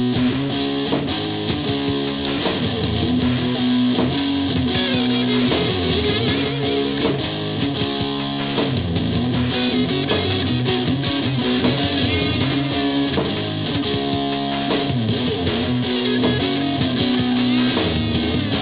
I used a cardboard box to get the gloomy tone.